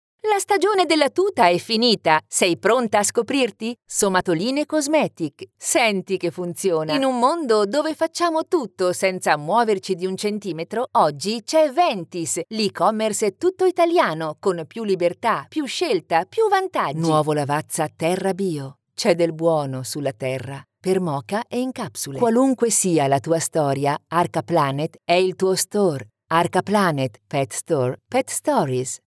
Italian female voice talent, Experienced, versatile, friendly, educated, assured.Warm and clear for narration, more young for commercial, professional and smooth for presentation
Sprechprobe: Werbung (Muttersprache):